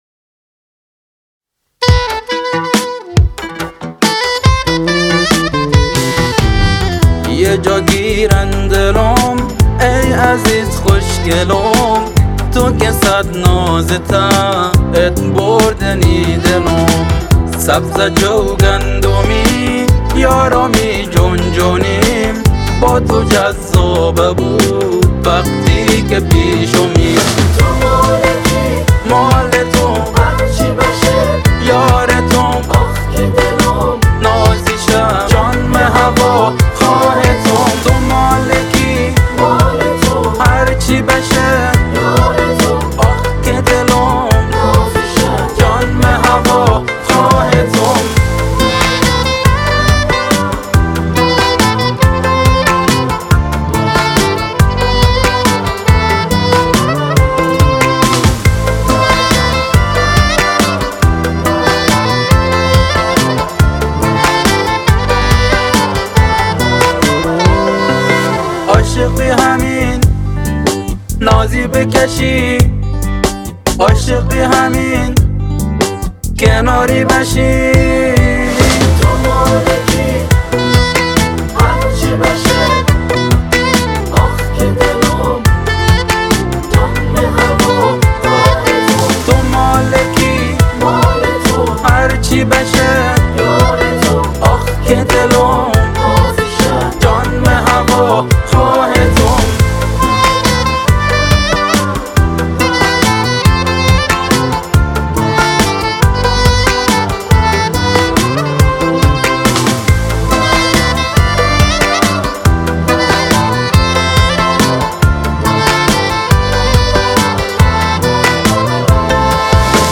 🎤 اثری شاد با صدای :
کلارینت